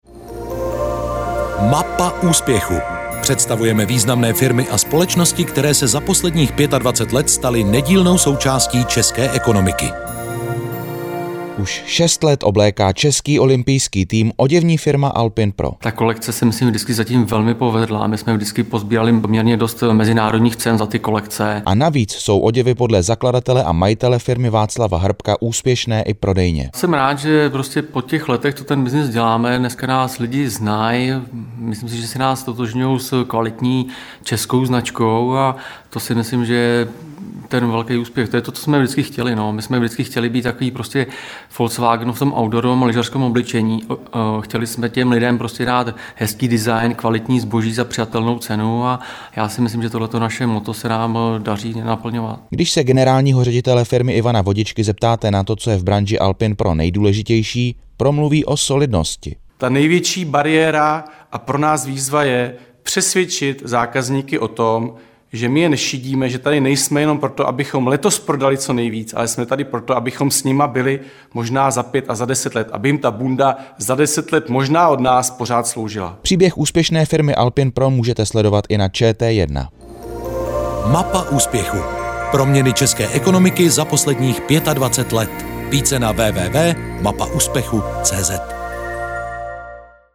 Alpine Pro, a.s. ve vysílání českého rozhlasu - CZECH TOP 100